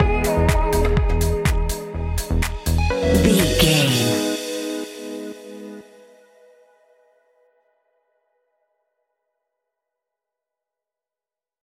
Aeolian/Minor
G#
groovy
uplifting
driving
energetic
repetitive
drum machine
synthesiser
piano
electro house
synth leads
synth bass